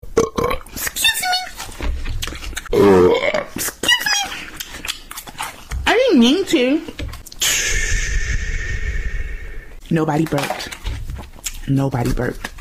excuse-me-nobody-burped-made-with-Voicemod-technology.mp3